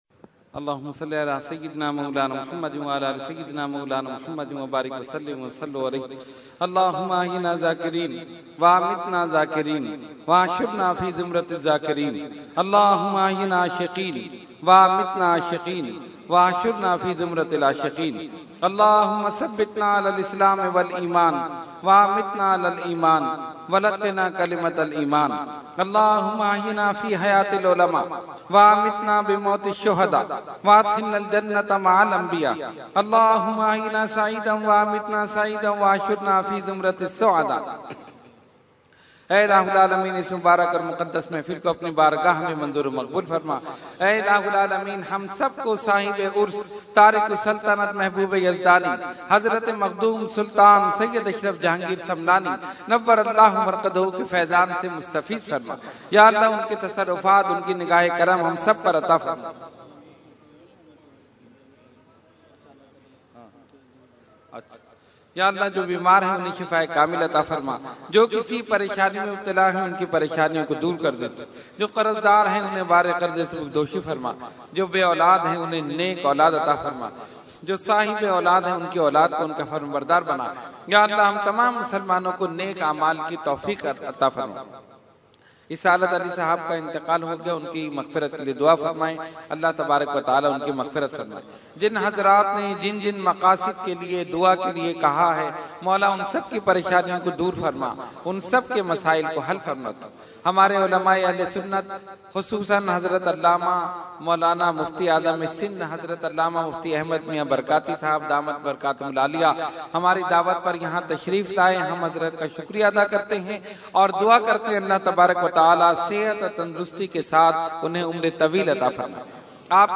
Dua – Urs e Makhdoom e Samnani 2015 – Dargah Alia Ashrafia Karachi Pakistan